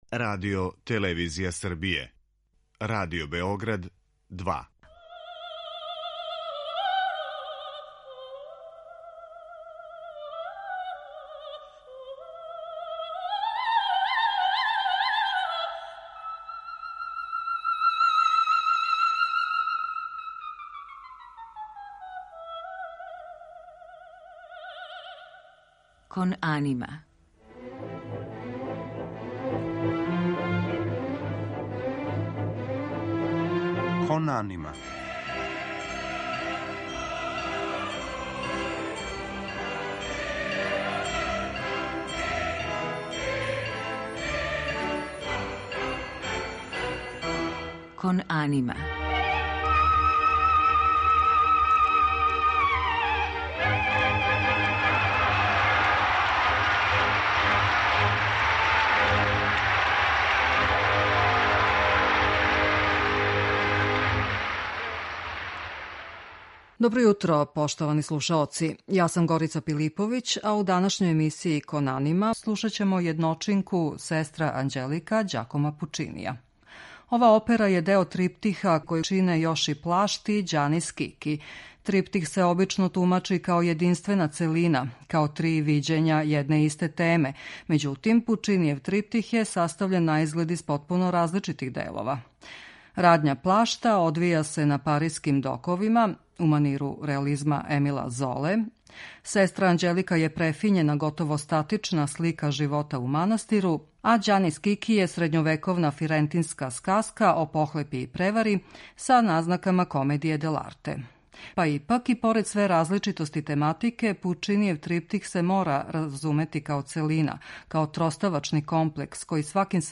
У данашњој емисији Кон анима можете слушати средишњи, лирски део триптиха - причу о несрећној жени која је послата у манастир зато што је родила ванбрачно дете.
Главну јунакињу су за казну послали у манастир и ова тешка социолошка тема подстакла је Пучинија не само да лирским, топлим бојама ослика мајчинску љубав већ и да изрази критику друштва и строгог живота у манастиру.
Кон анима је посвећена опери.